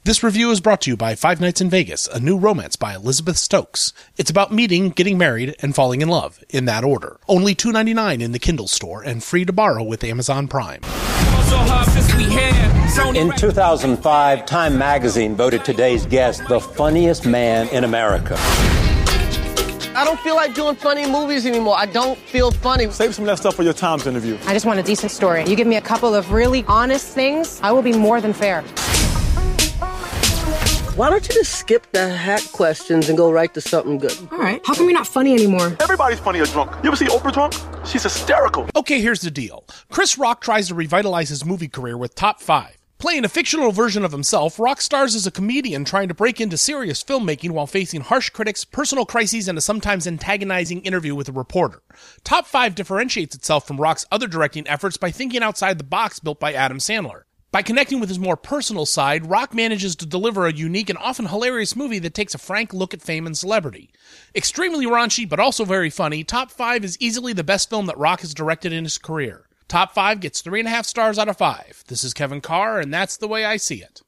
Top Five’ Movie Review